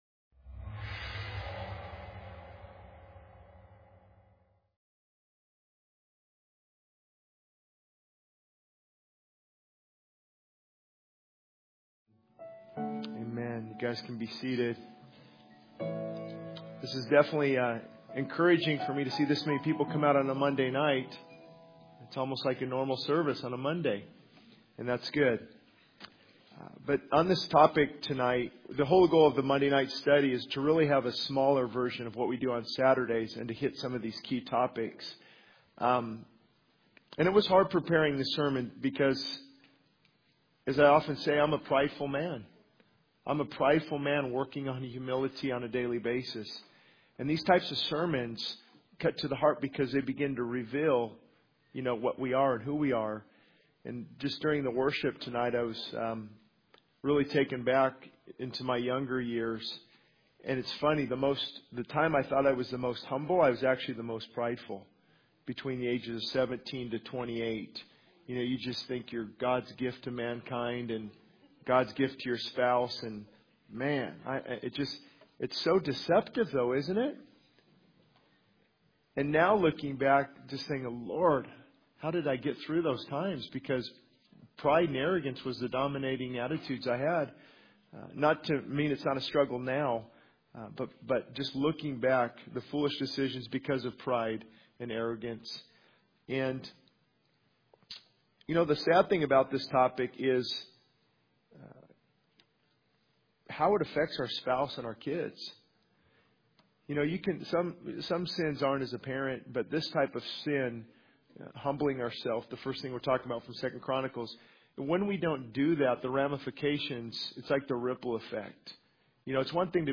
The sermon serves as a call to action for believers to humble themselves before God to experience His forgiveness and healing.